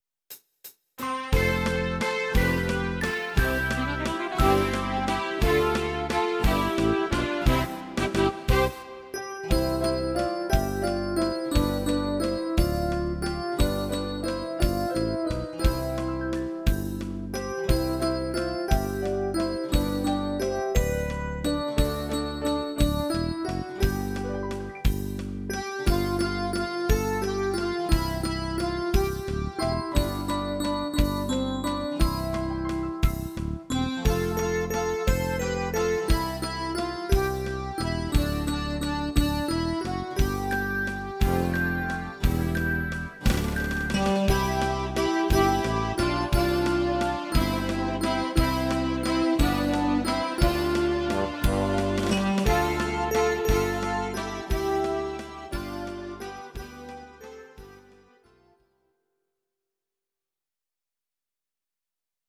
Audio Recordings based on Midi-files
Oldies, German, 1950s